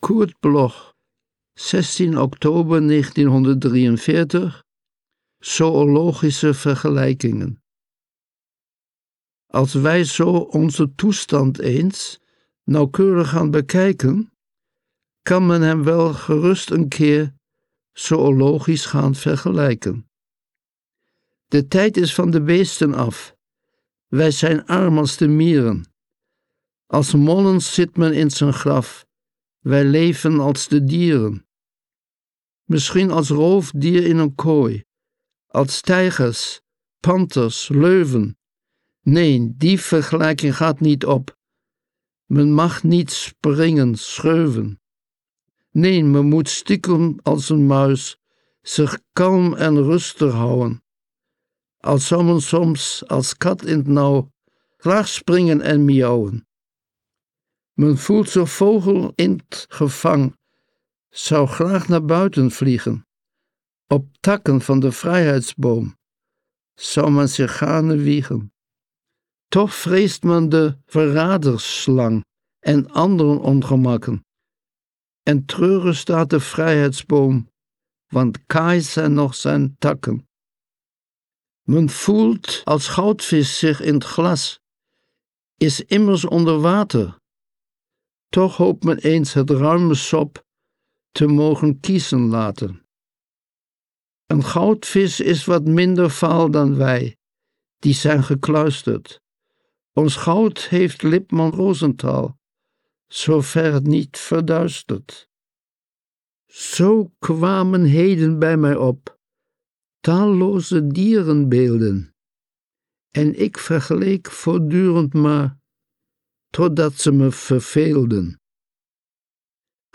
Aufnahme: Mainmix, Schwerte · Bearbeitung: Kristen & Schmidt, Wiesbaden